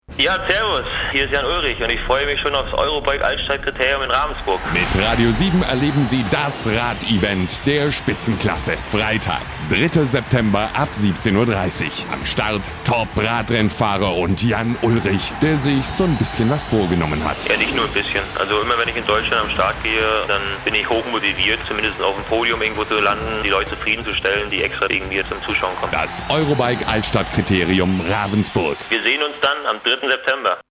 > Radio7 Spot mit Jan Ullrich (mp3 Datei mit 720 kB)
Radio7_Jan_Ullrich_Trailer_40Sendungen.mp3